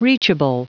Prononciation du mot reachable en anglais (fichier audio)
Prononciation du mot : reachable